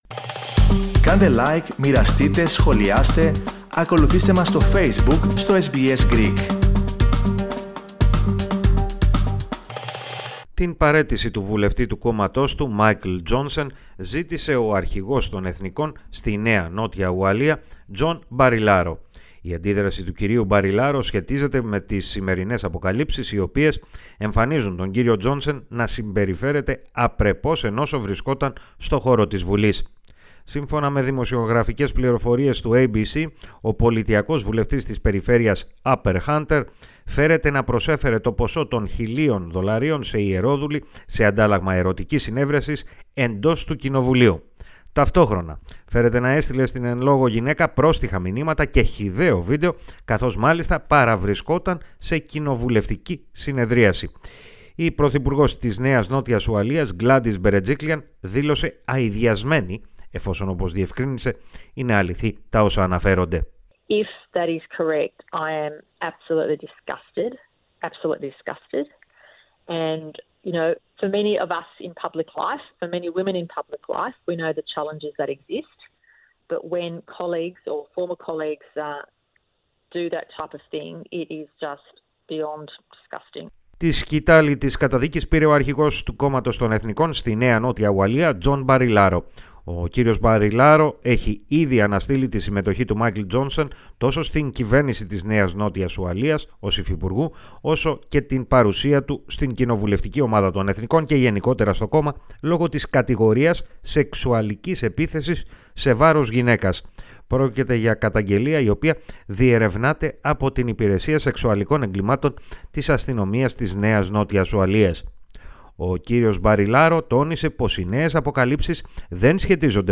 Την ίδια στιγμή, εντείνονται οι φωνές που ζητούν την άμεση απομάκρυνση του ομοσπονδιακού βουλευτή των Φιλελευθέρων Άντριου Λάμινγκ. Περισσότερα και για τα δύο θέματα ακούστε στη σχετική αναφορά